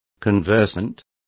Complete with pronunciation of the translation of conversant.